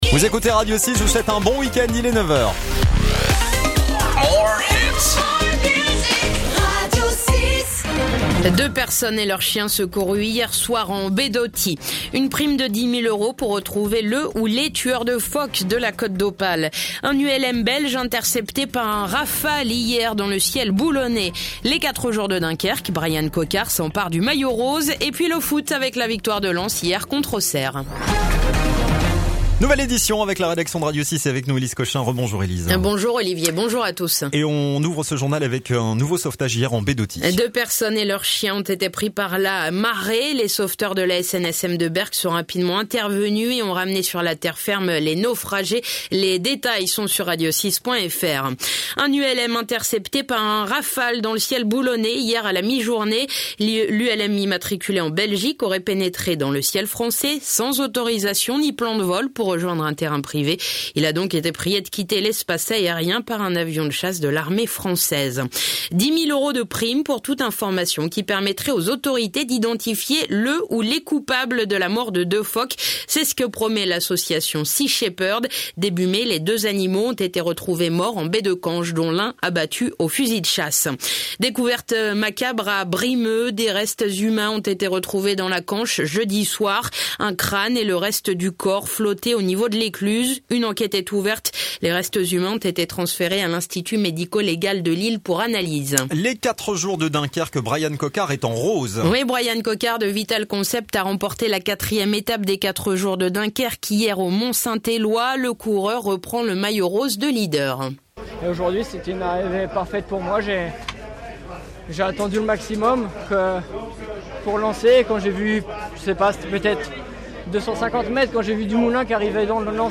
Le journal côte d'opale du samedi 12 mai